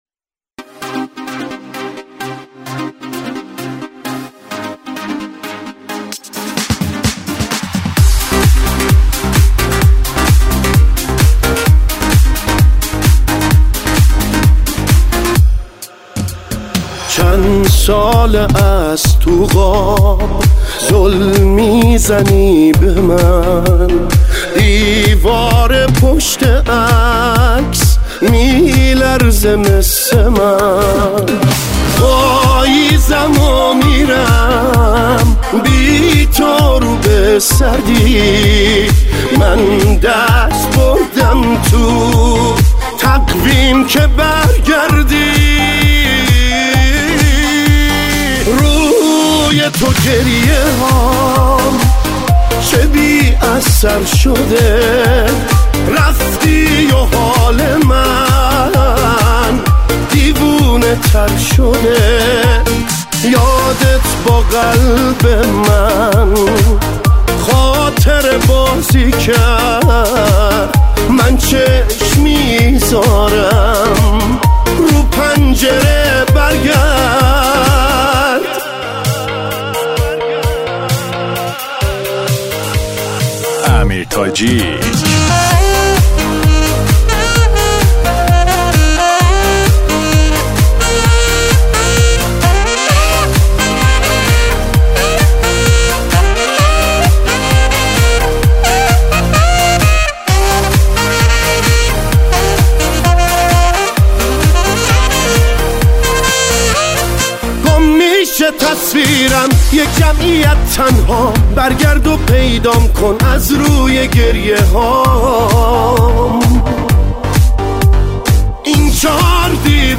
موسیقی پاپ
خواننده سبک پاپ است